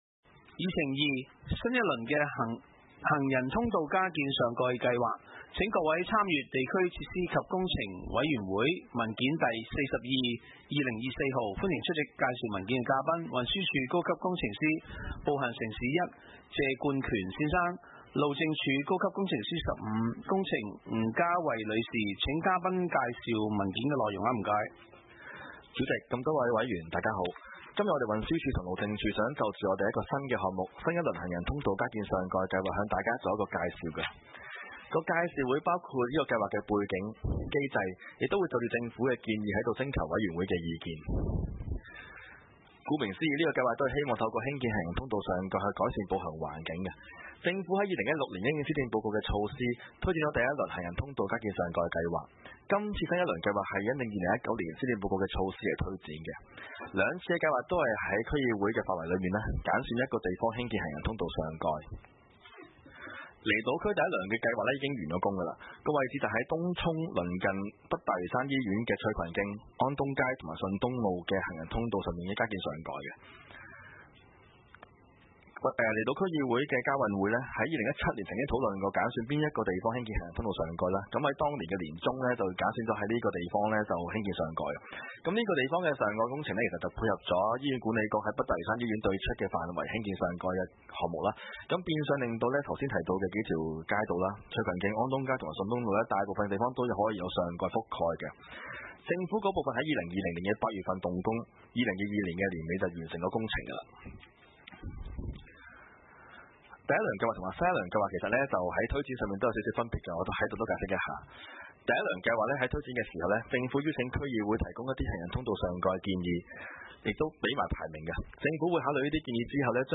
地區設施及工程委員會文件 2024年12月16日會議 與會的區議員經過討論 最終一致採納「運輸署」及「路政署」的意見 在「逸東邨」對出行人路加建上蓋 日後「裕泰苑」居民可以不用撐傘，都可以暢達往返「東涌西站」及「裕泰苑」！